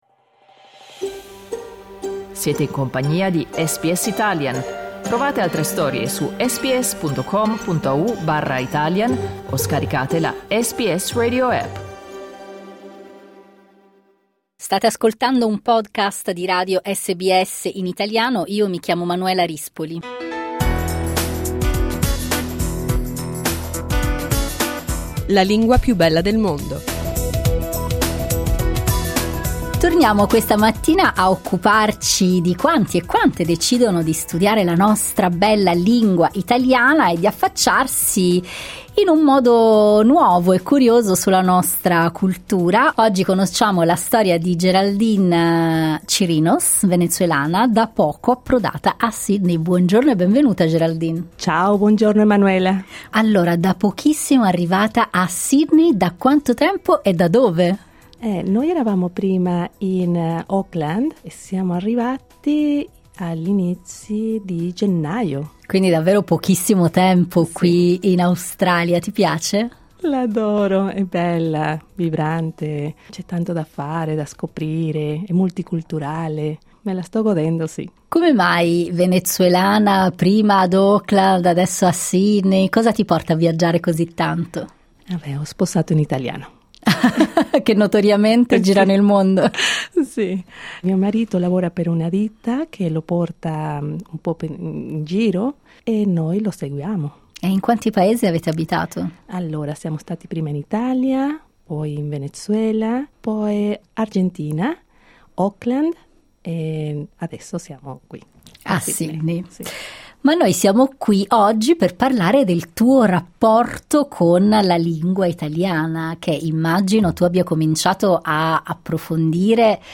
Clicca sul tasto "play" in alto per ascoltare l'intervista Oggi vive in Australia, ma il suo percorso personale e linguistico è iniziato anni fa in Italia, dove si era trasferita con il marito.